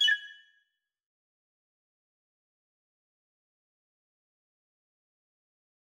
error_style_4_005.wav